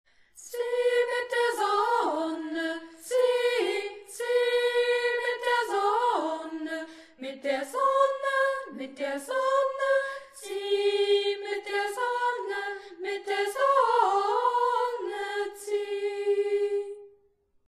Das Lied wurde für Sie vom Sextett der Freien Waldorfschule Halle eingesungen.
Halle Zieh mit der Sonne einstimmig.mp3